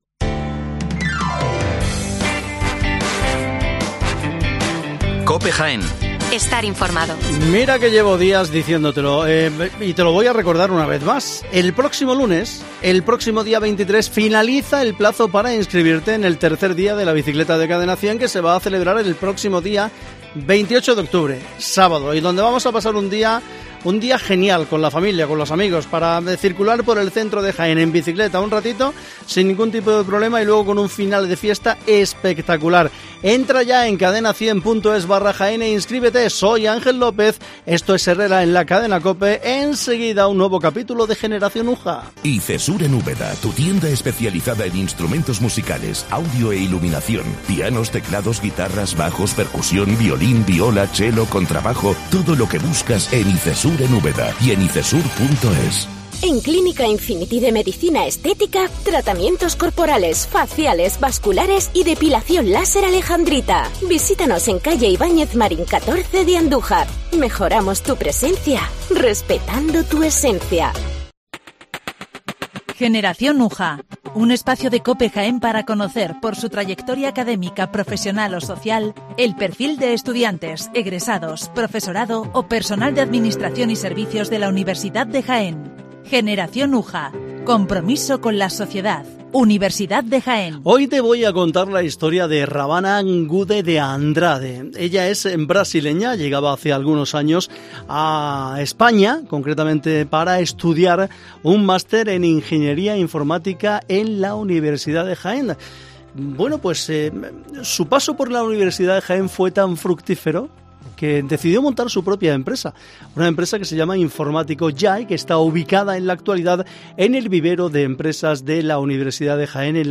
Nuestra invitada de hoy